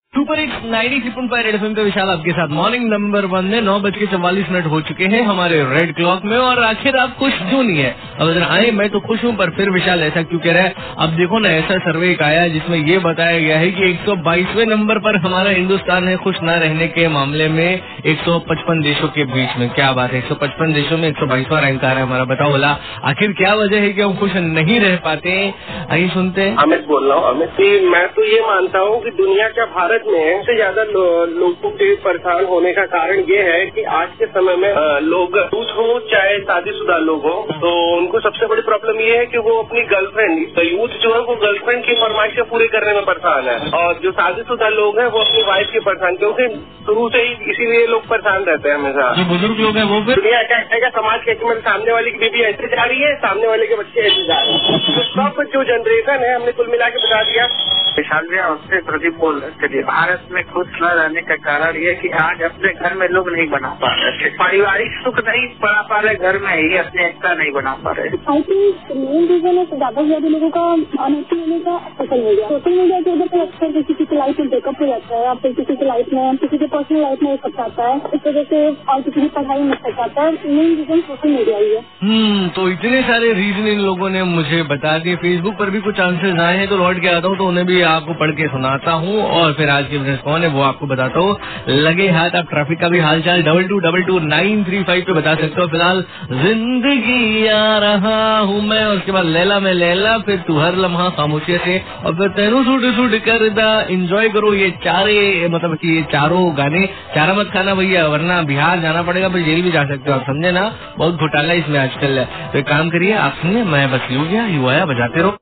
CALLERS BYTE ABOUT SURVEY